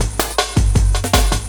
06 LOOP06 -R.wav